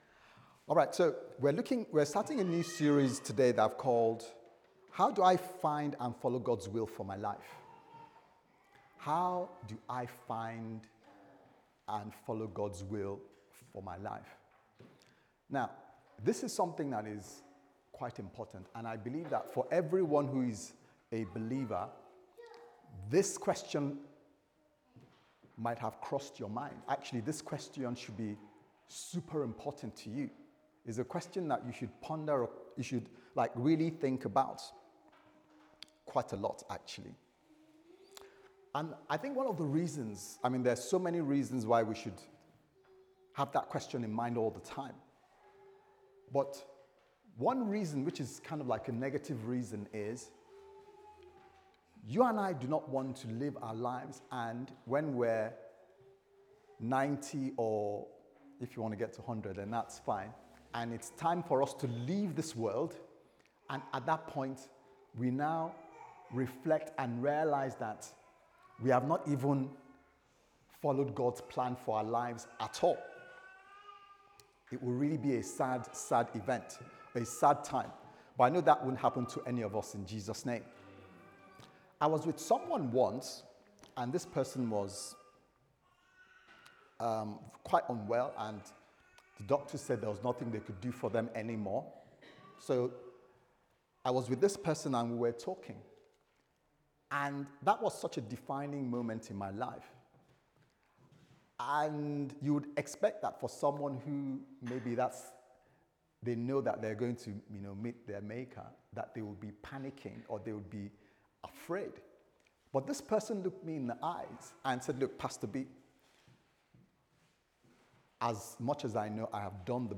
How Can I Find God's Will For My Life Service Type: Sunday Service Sermon « I Met Jesus On An Ordinary Day